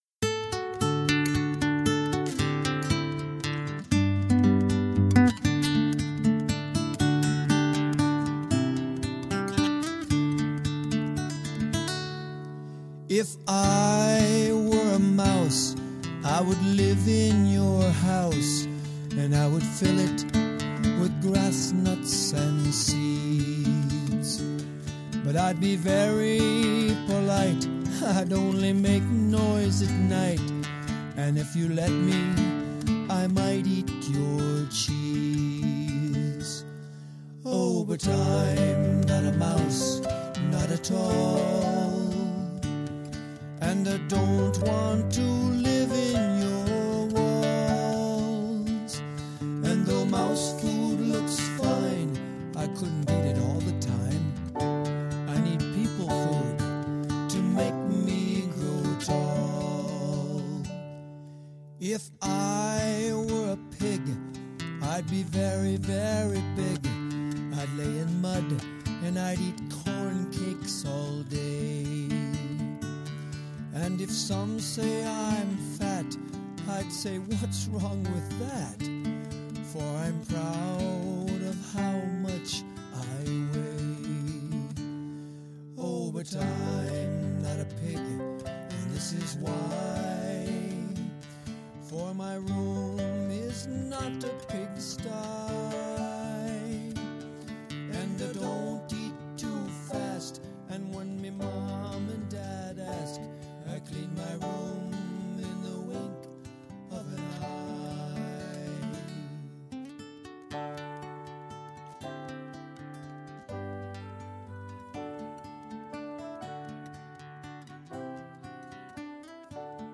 Children
and recording music and movement songs for over 20 years.